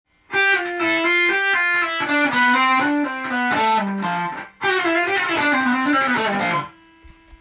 譜面通り弾いてみました
ピッキングをしないで弾くのでハンマリング、ピリングのみで弾いていきます。
レガート奏法と言われる弾き方です。